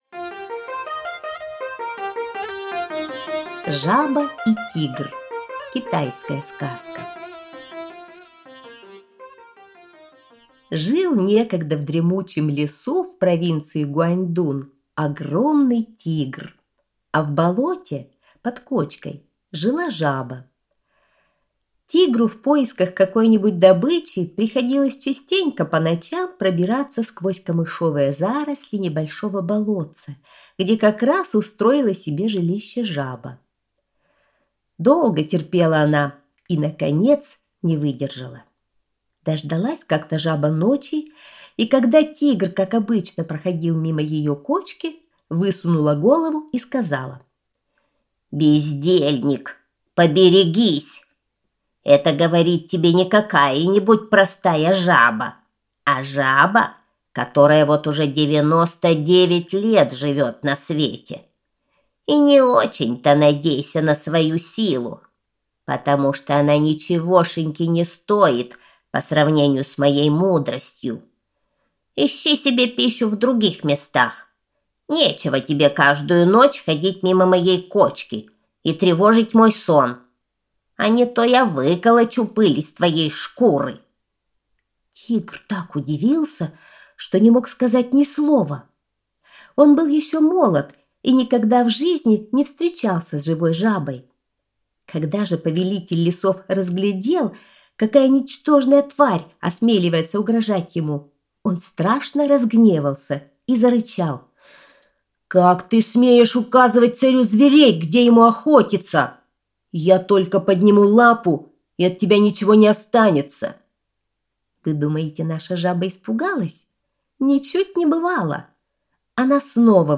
Жаба и тигр – китайская аудиосказка